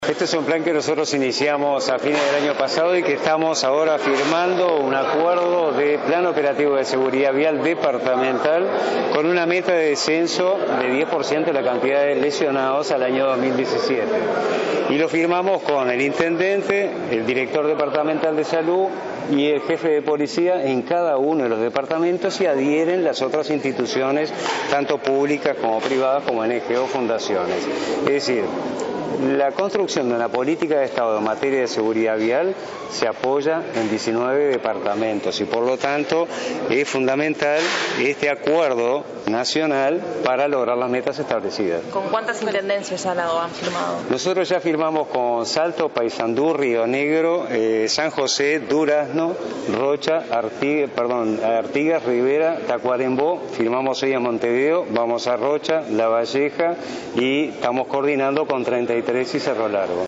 La Unasev acordó con la Intendencia de Montevideo la implementación de un Plan Operativo de Seguridad Vial para el descenso de la siniestralidad vial en el departamento. El director Gerardo Barrios destacó que la meta es bajar 10% la cantidad de lesionados a fin de año. Añadió que se busca lograr ese objetivo en todos los departamentos.